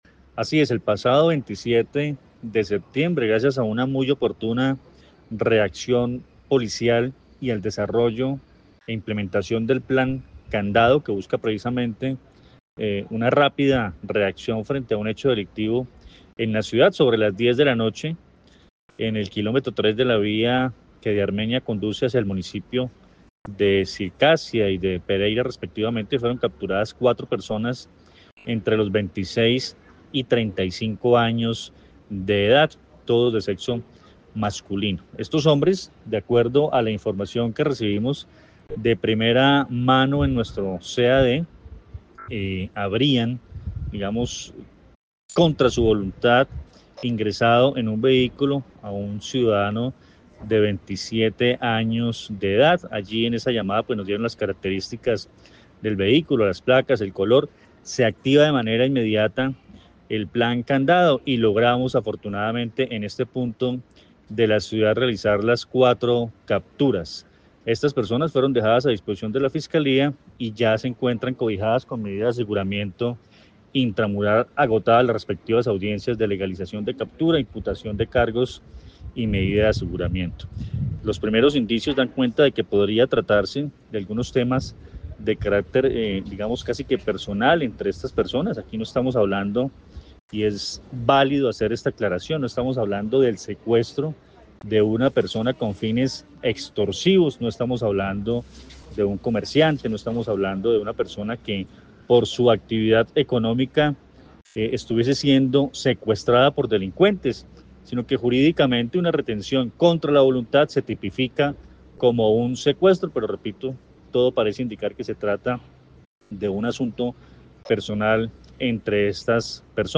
Coronel Luis Fernando Atuesta, comandante de la Policía del Quindío